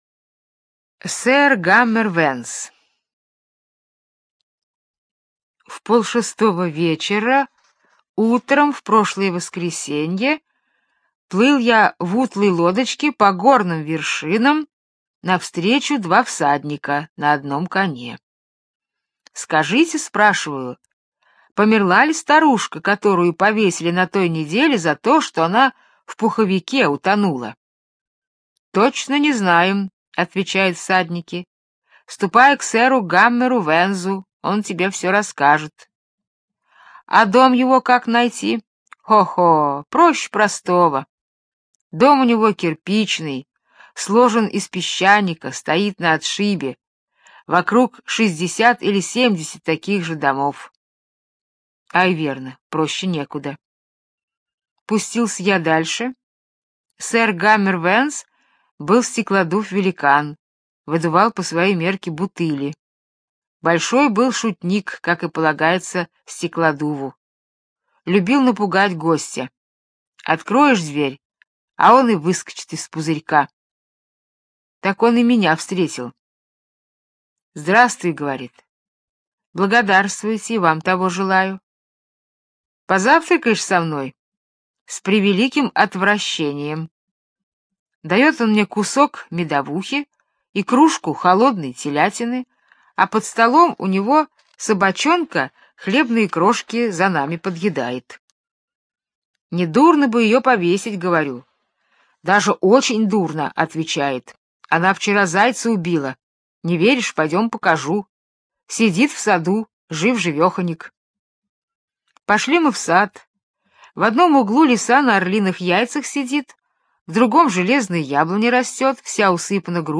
Сэр Гаммер Венз - британская аудиосказка - слушать онлайн